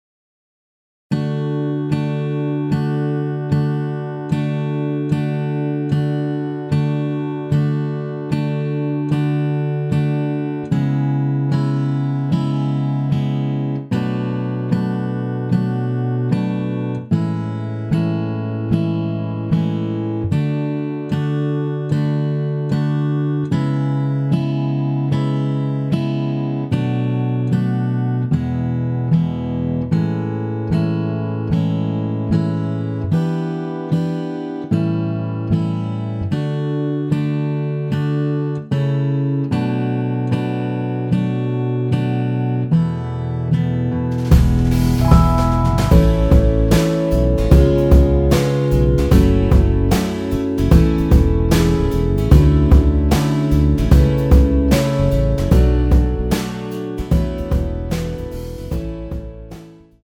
원키에서(-1)내린 MR입니다.
D
앞부분30초, 뒷부분30초씩 편집해서 올려 드리고 있습니다.